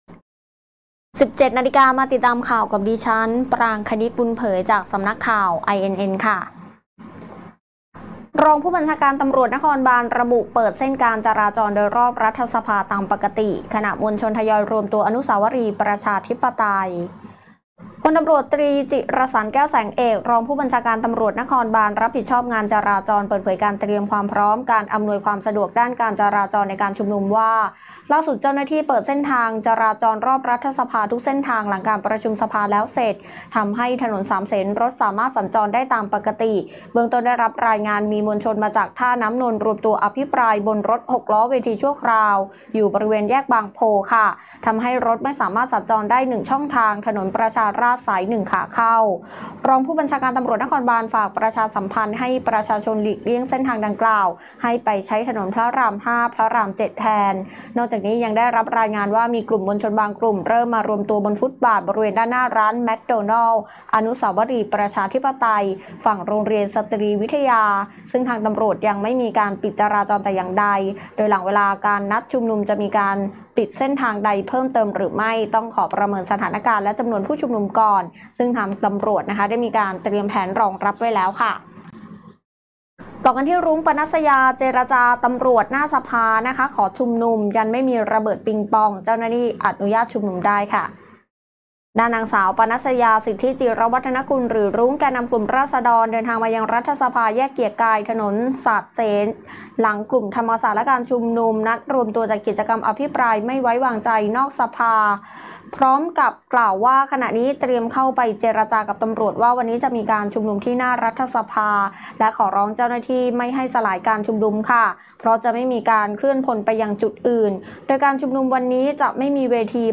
ข่าวต้นชั่วโมง 17.00 น.